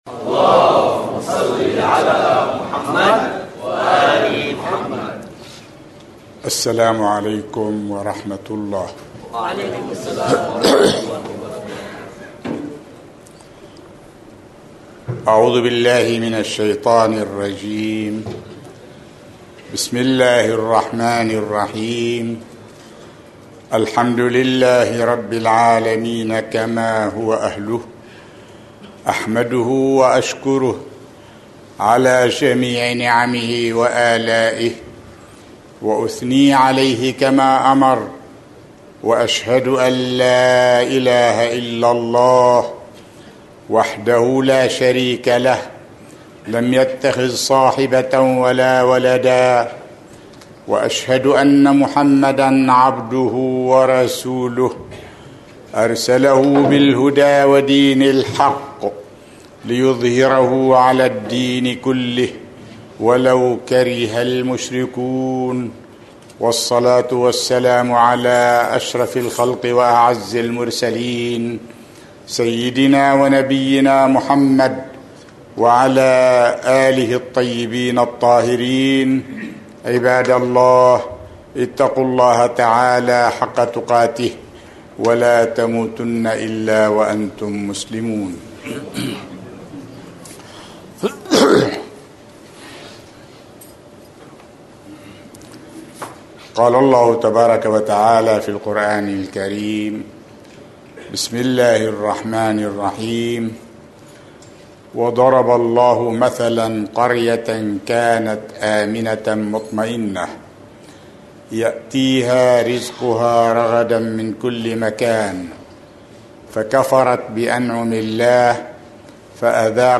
خطبة الجمعة